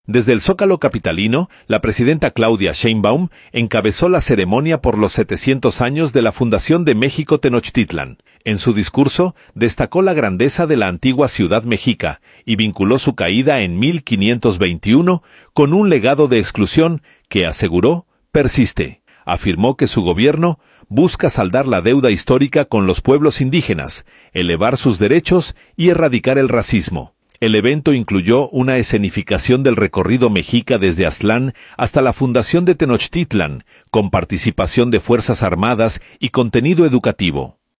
Desde el Zócalo capitalino, la presidenta Claudia Sheinbaum encabezó la ceremonia por los 700 años de la fundación de México-Tenochtitlan. En su discurso, destacó la grandeza de la antigua ciudad mexica y vinculó su caída en 1521 con un legado de exclusión que, aseguró, persiste. Afirmó que su gobierno busca saldar la deuda histórica con los pueblos indígenas, elevar sus derechos y erradicar el racismo.